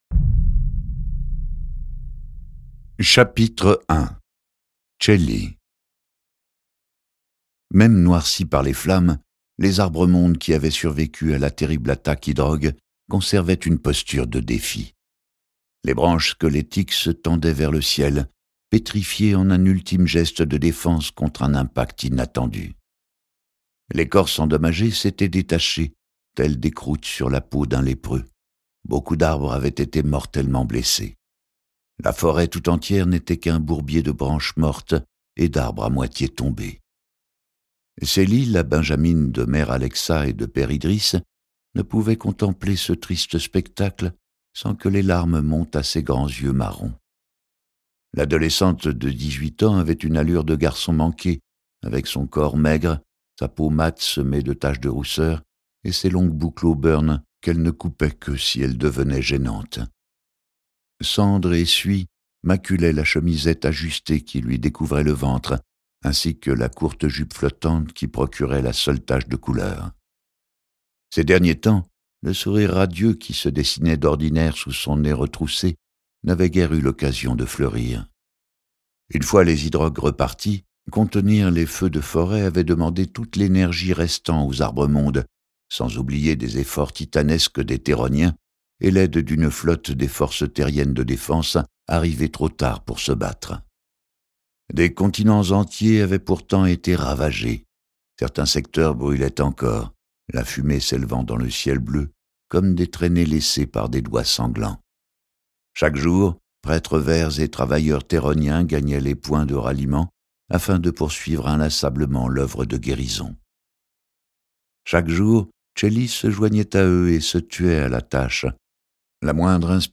Mais ce fragile espoir sera ébranlé par une nouvelle trahison - encore plus brutale que les précédentes...Ce livre audio est interprété par une voix humaine, dans le respect des engagements d'Hardigan.